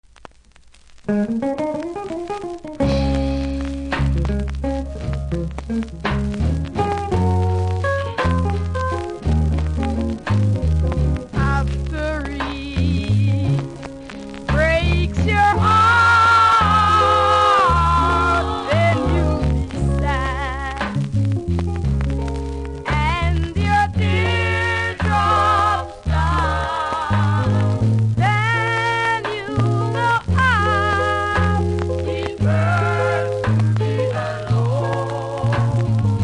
残念ながらこちらサイドはノイズ多々あります。